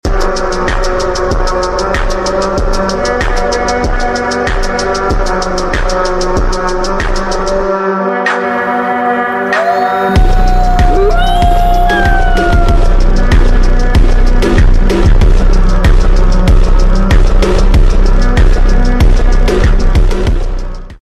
I Love Wolves🐺 ||| Wolf Sound Effects Free Download